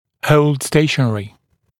[həuld ‘steɪʃənərɪ][хоулд ‘стэйшэнэри]удерживать в неподвижном положении (напр. о коронке при перемещении корня)